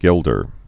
(gĕldər)